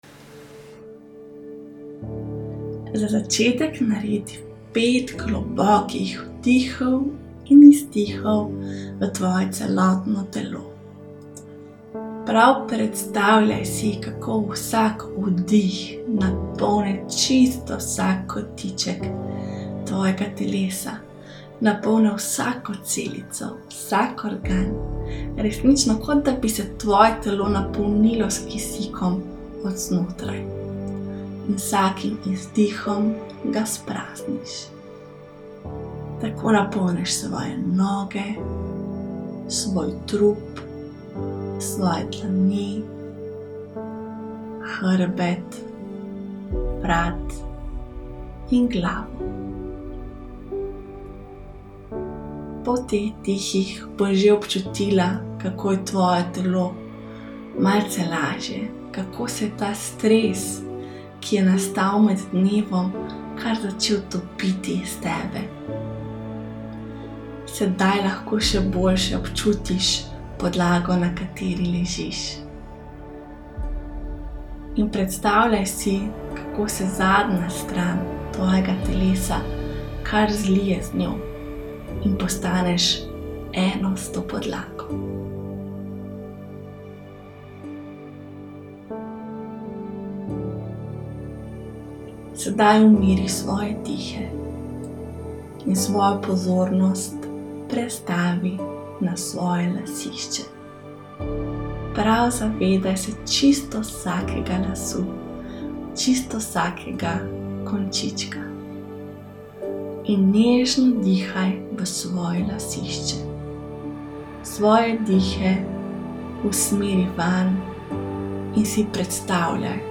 Meditacija-za-sproscanje.mp3